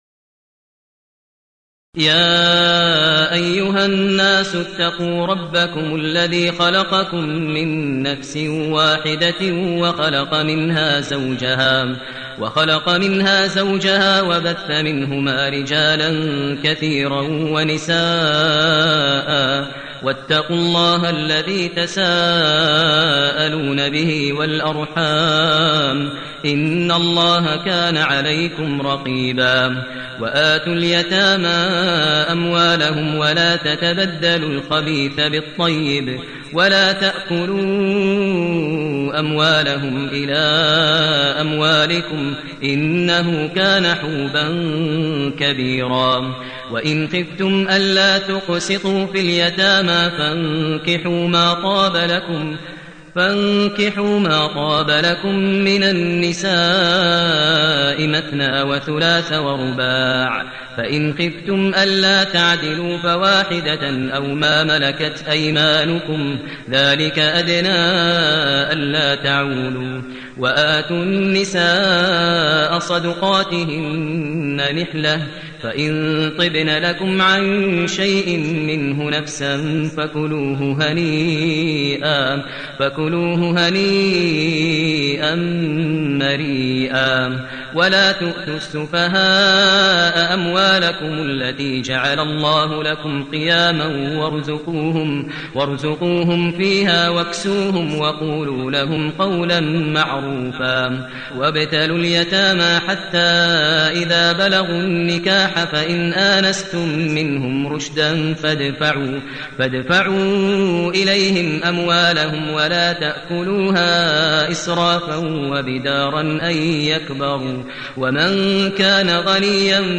المكان: المسجد النبوي الشيخ: فضيلة الشيخ ماهر المعيقلي فضيلة الشيخ ماهر المعيقلي النساء